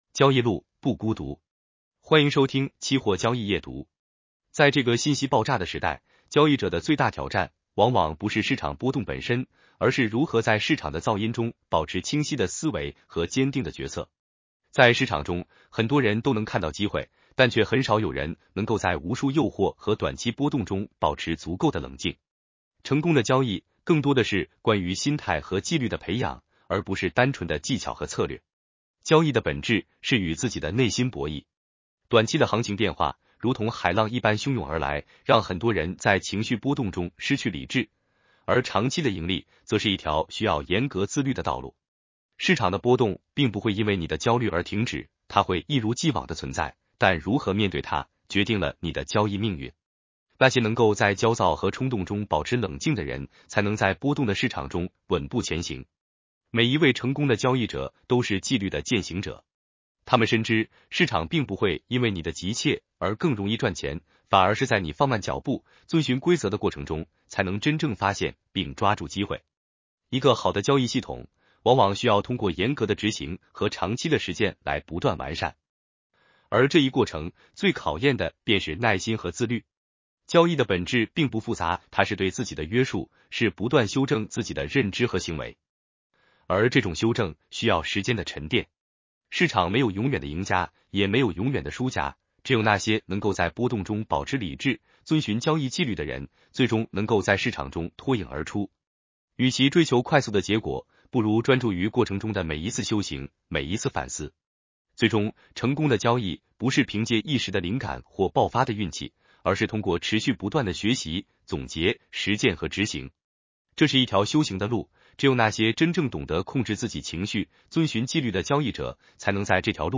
男生普通话版 下载mp3 交易路，不孤独。